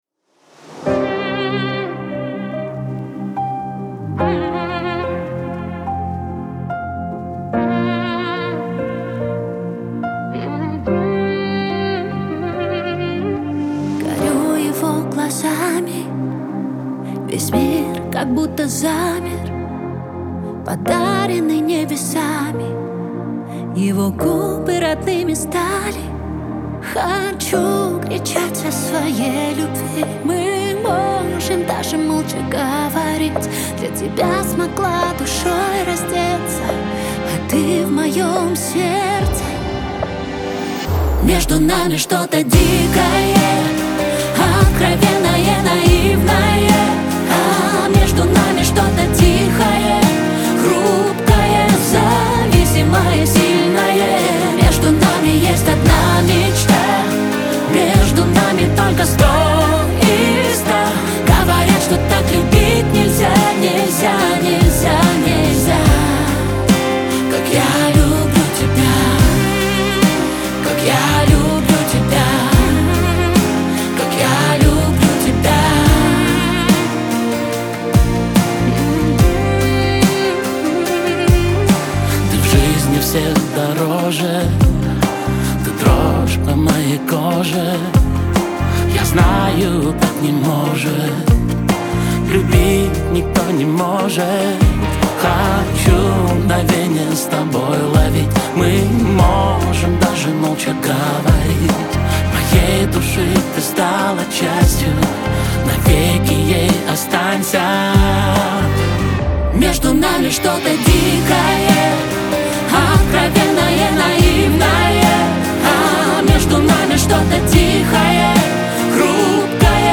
pop , дуэт
эстрада